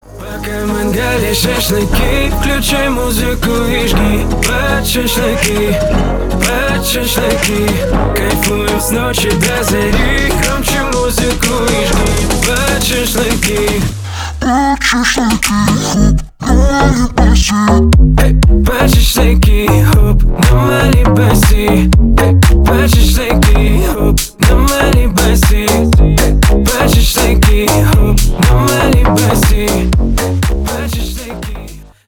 Танцевальные
клубные